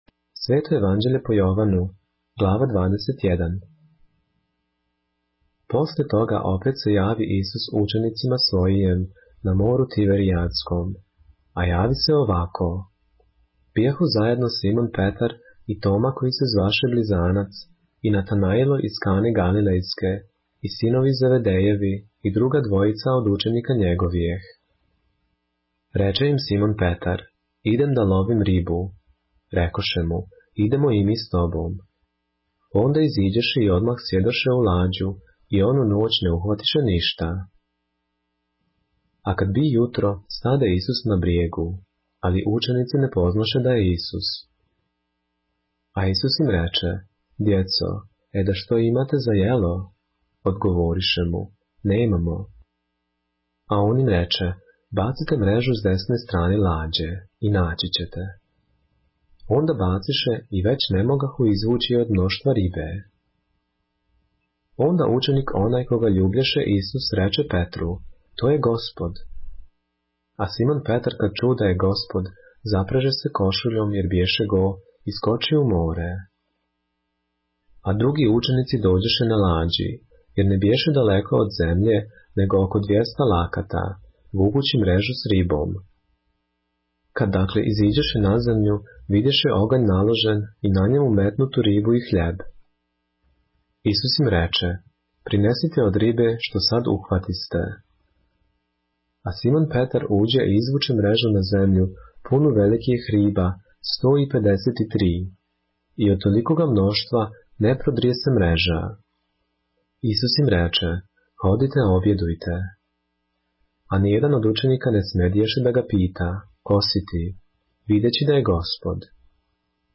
поглавље српске Библије - са аудио нарације - John, chapter 21 of the Holy Bible in the Serbian language